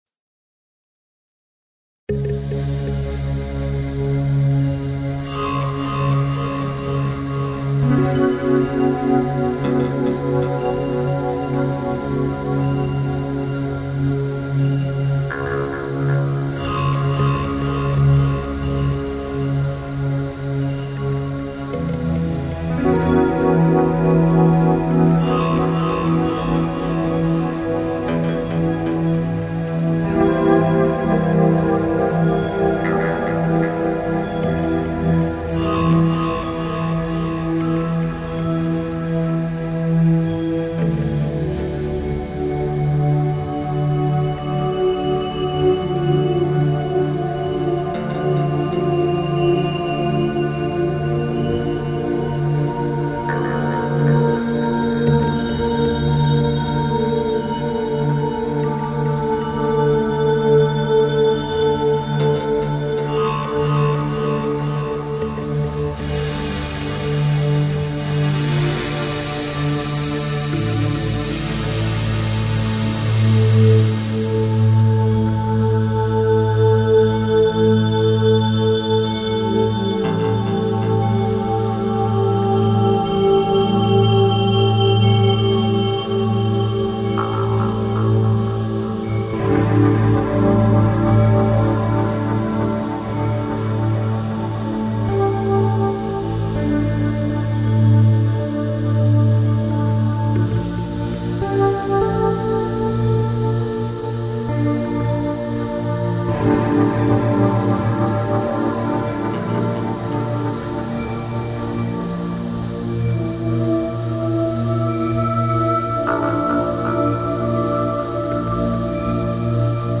ILLUSTRATIVE MUSIC ; QUIET MUSIC